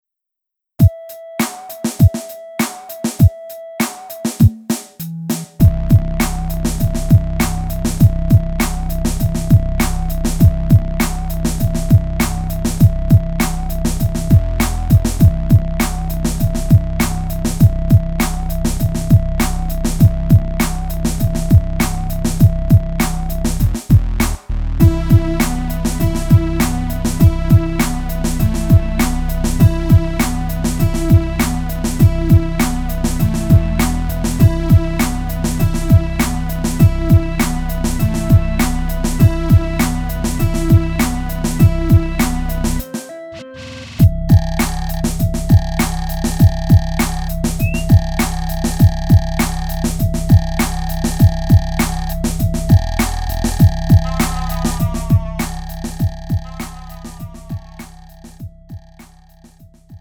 음정 -1키 2:37
장르 가요 구분 Lite MR
Lite MR은 저렴한 가격에 간단한 연습이나 취미용으로 활용할 수 있는 가벼운 반주입니다.